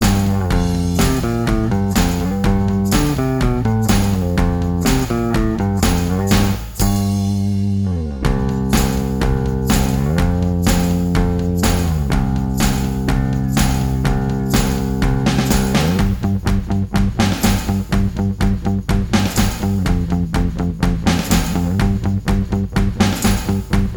Minus Guitars Pop (1980s) 2:46 Buy £1.50